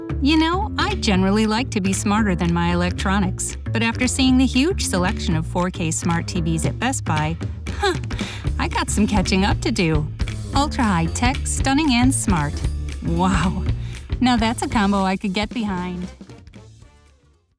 My voice has been described as deep, warm, musical, clear or bright, and caring or nurturing (i.e.
Radio Commercials Commercial Demo, 5 Spots